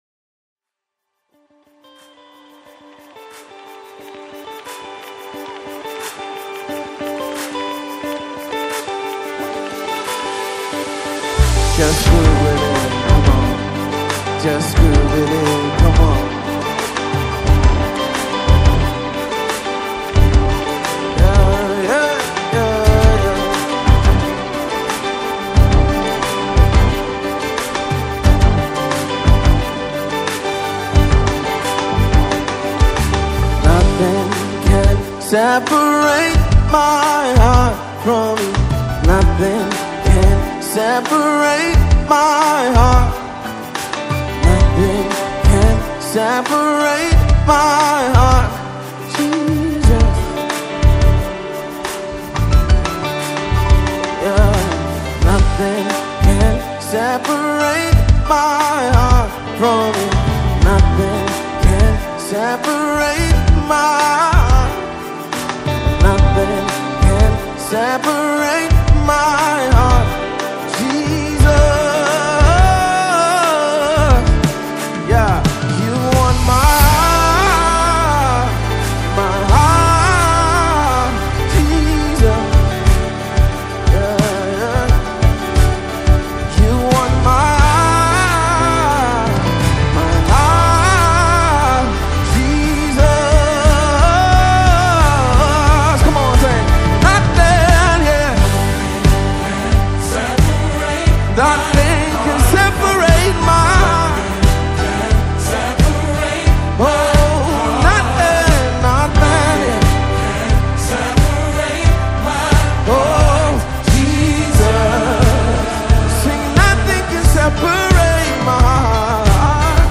This heartfelt performance will move you to your core.